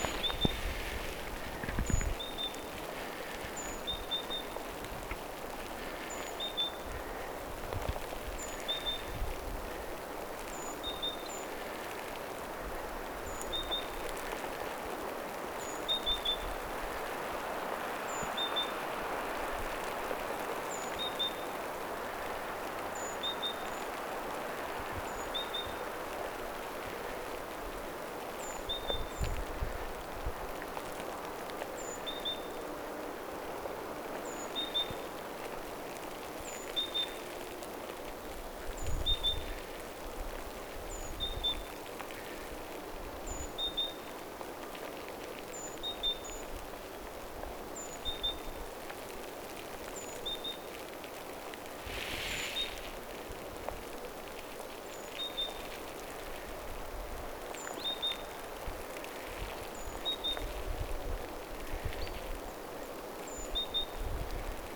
tuollaista aika hienoa talitaisen laulua?
tuollaista_hienoa_talitiaisen_laulua.mp3